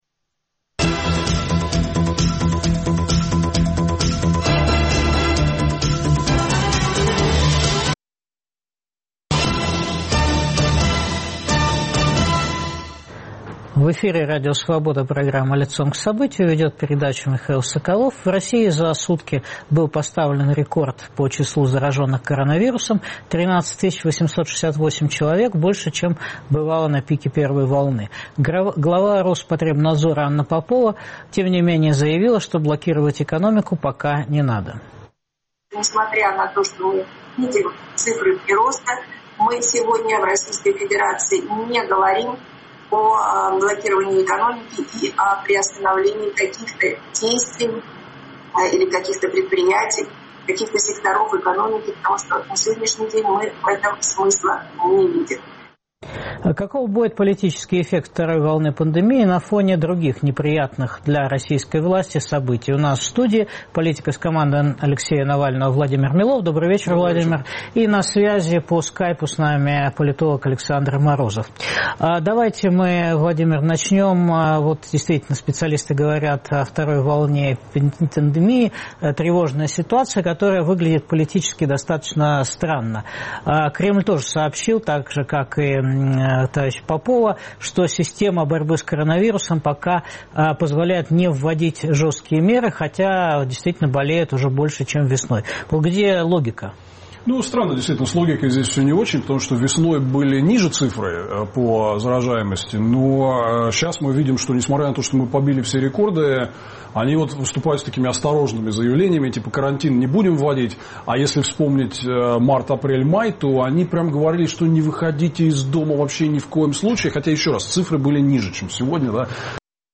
Может ли "вторая волна" пандемии разрушить стабильность режима Путина? Обсуждают политик Владимир Милов
Интервью